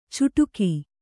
♪ cuṭuki